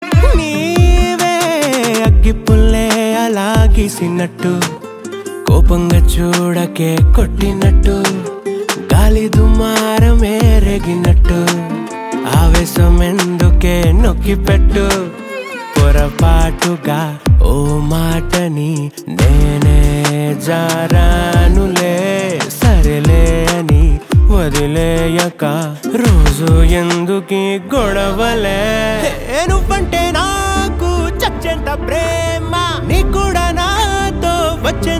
energetic
loud, clear sound
best flute ringtone download | dance song ringtone